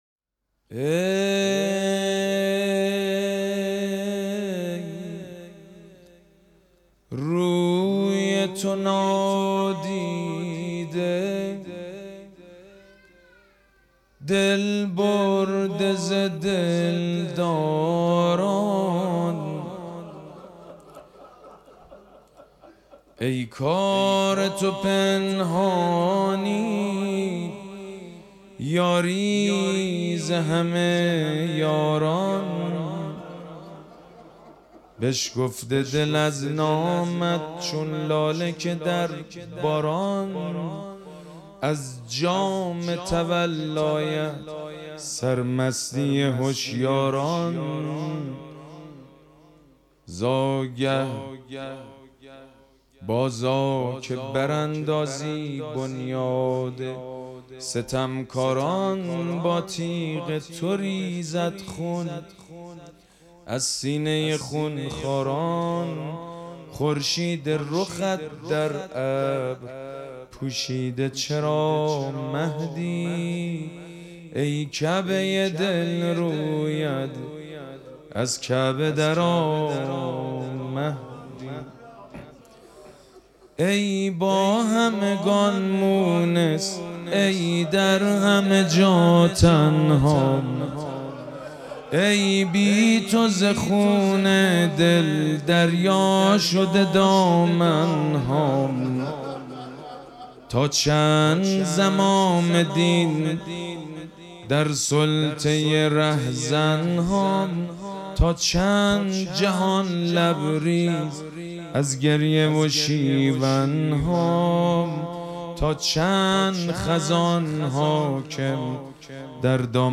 مراسم جشن ولادت حضرت صاحب الزمان (عج)
مدح
حاج سید مجید بنی فاطمه